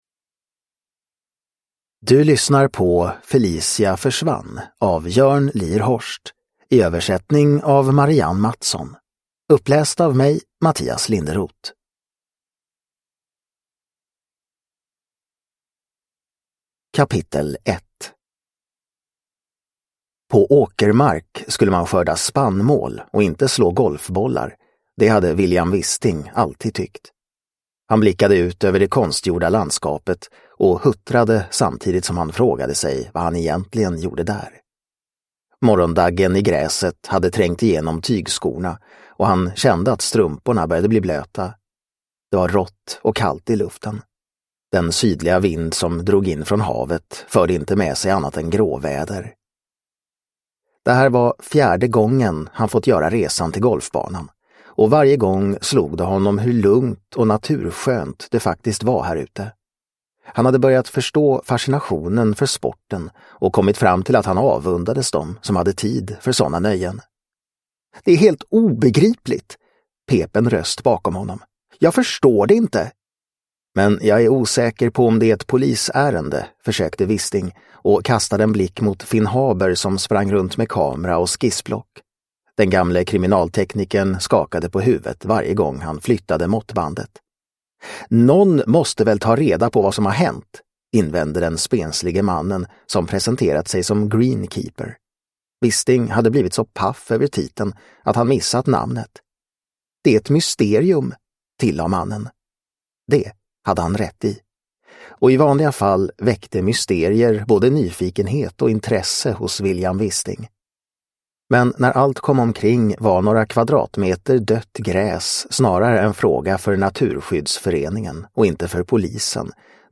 Felicia försvann – Ljudbok – Laddas ner